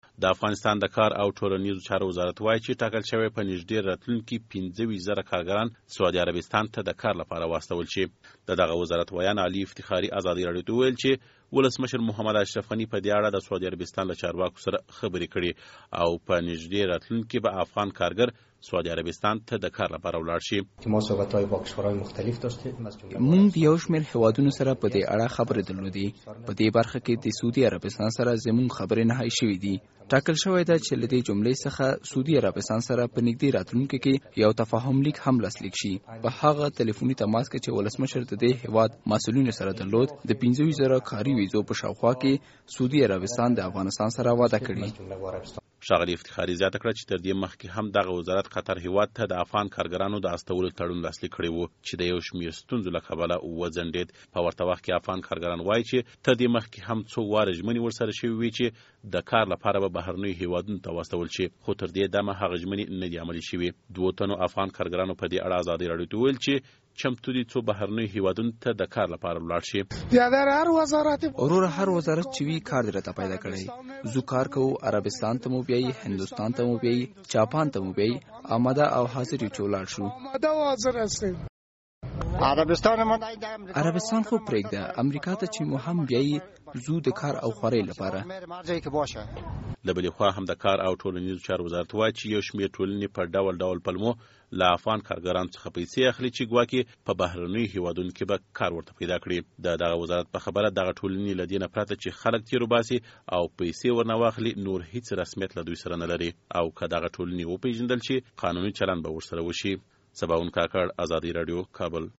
دوو تنو افغان کارګرانو په دې اړه ازادي راډیو ته وویل چې چمتو دي څو بهرنیو هېوادونو ته د کار لپاره ولاړ شي.